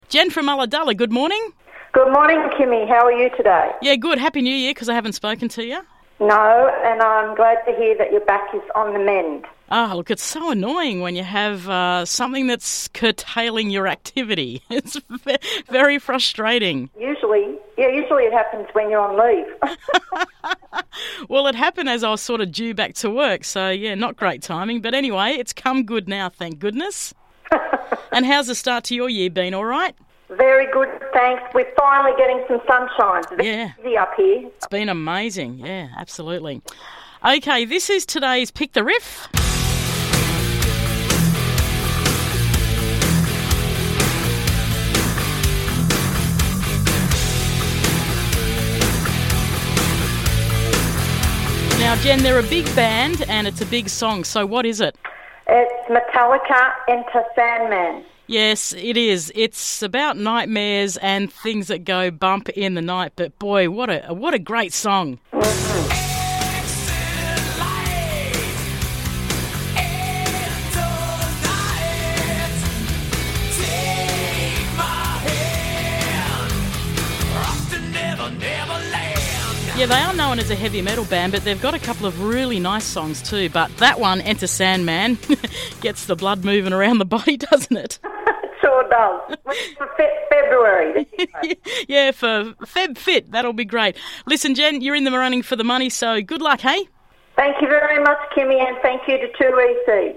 A heavy riff from some heavy metal heavy hitters!